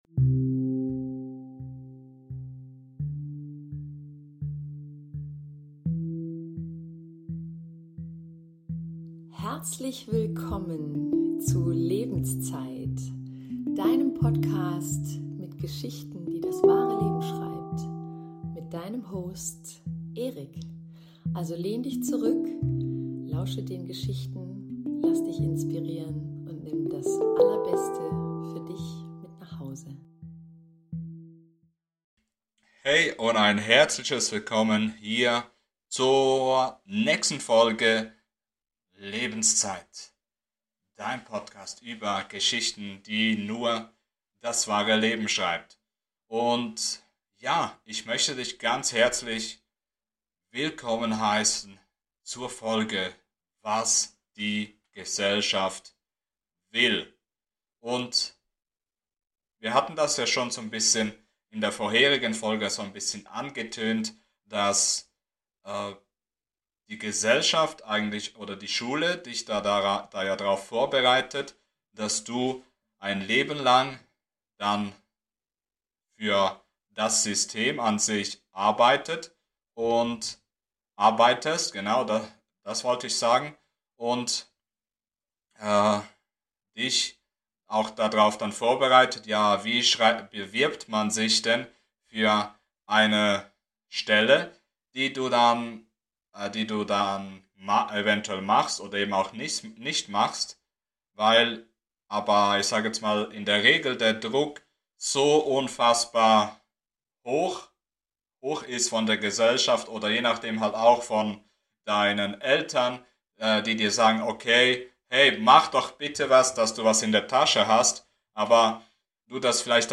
Musik
Anmoderation